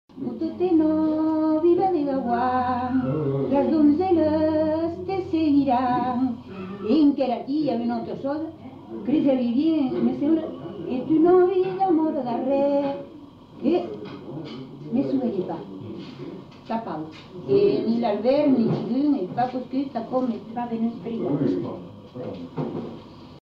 Genre : chant
Effectif : 1
Type de voix : voix de femme
Production du son : chanté